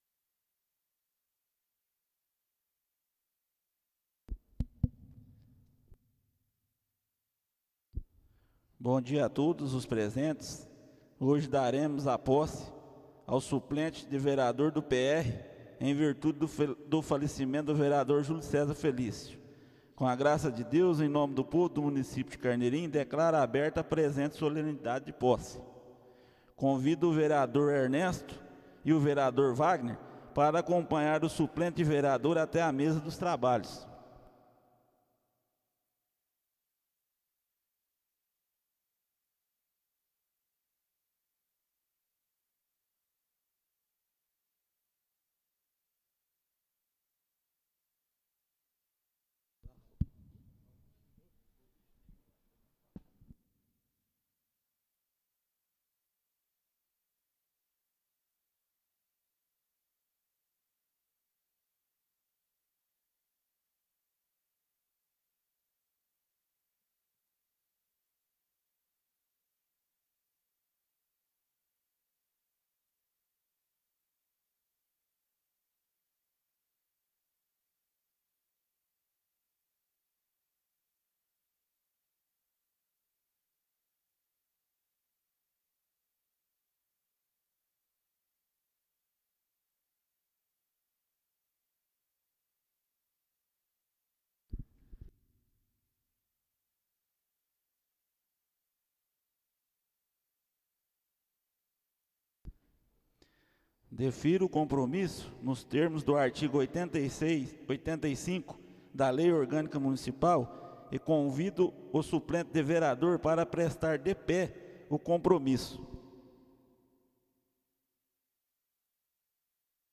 Áudio da cerimônia de posse do Ver. Daniel Marques, na sala de sessões da Câmara Municipal de Carneirinho, Estado de Minas Gerais.